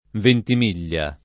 [ ventim & l’l’a ]